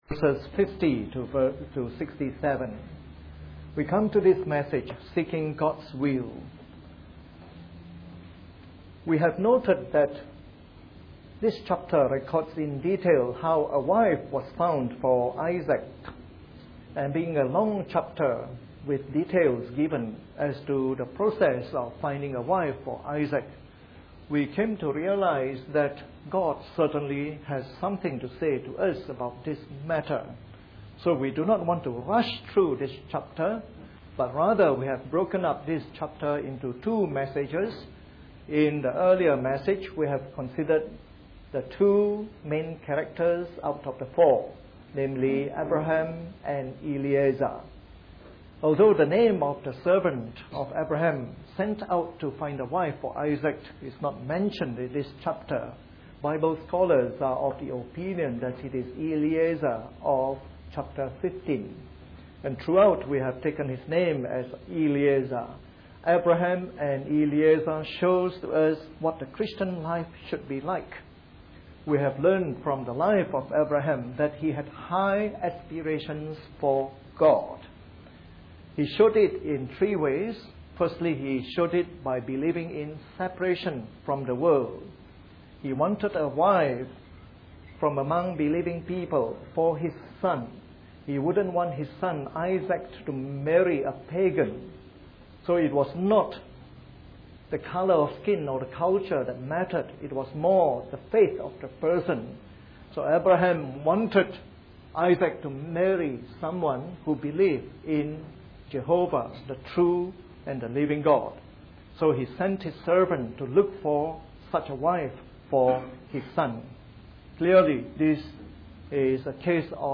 Part of our series on the Book of Genesis delivered in the Morning Service.